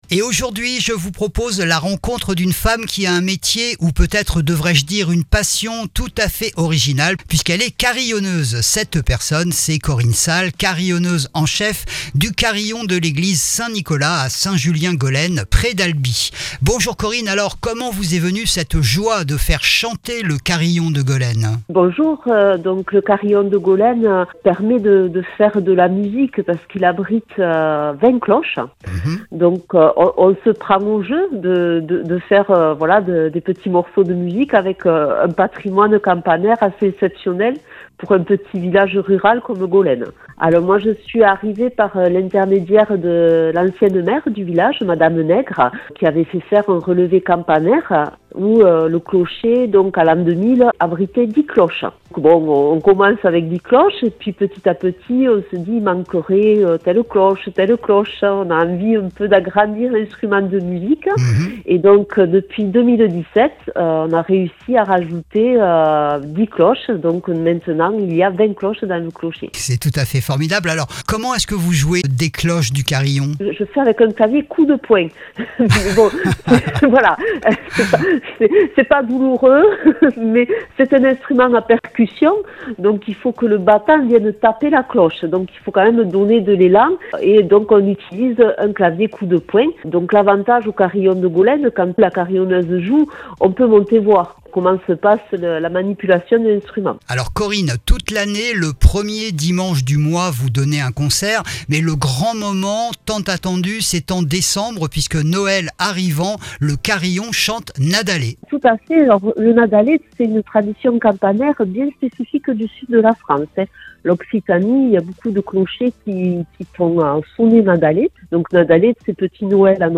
Le carillon de Gaulène va chante Nadalet !